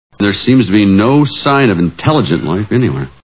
Toy Story Movie Sound Bites
Tim Allen as Buzz Lightyear: "There seems to be no sign of intelligent life anywhere."